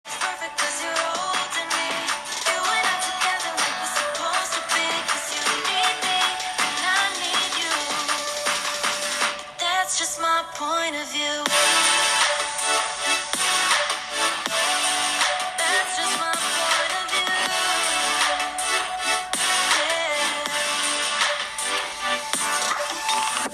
Nothing Phone (2)は、ステレオスピーカーを搭載。
肝心の音については、"良い"印象です。
▼Nothing Phone (2)のステレオスピーカーの音はこちら！
従来比で音圧、音量が大幅向上。